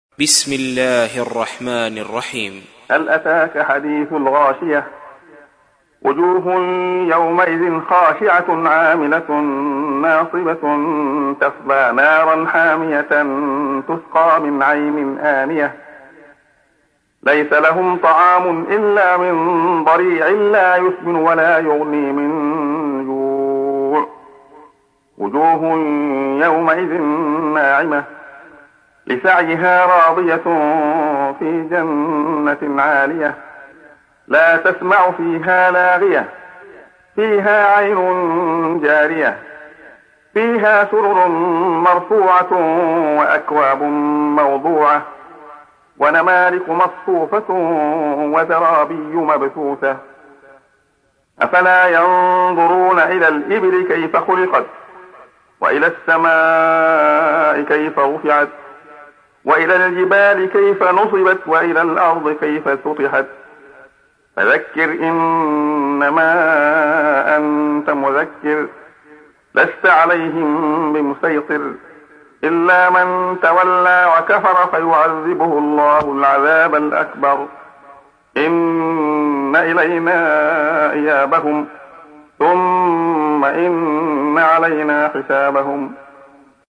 تحميل : 88. سورة الغاشية / القارئ عبد الله خياط / القرآن الكريم / موقع يا حسين